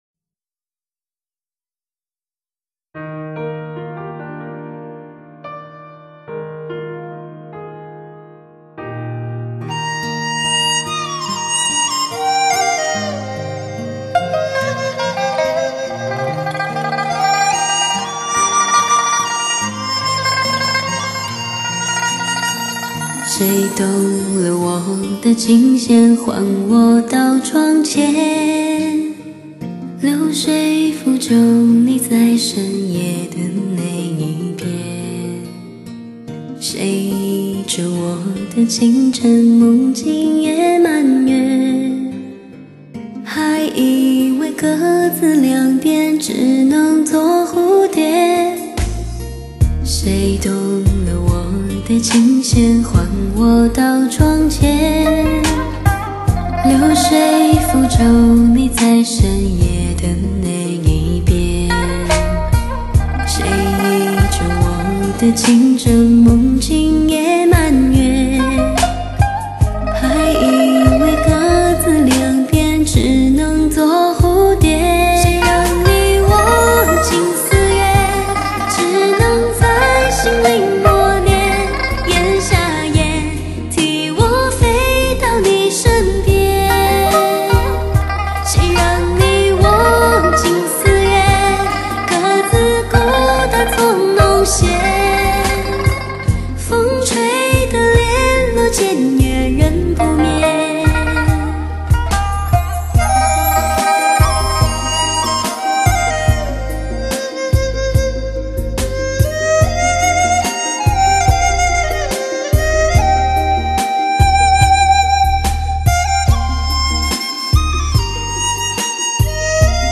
清似醴泉一样的歌者，醇如美酒的嗓音；不经意地又拨动了你的心弦，醉了你的心!
至真至纯绝靓女声，再次震撼发烧乐坛，让您的音响一起感受，纯天然的女声磁场!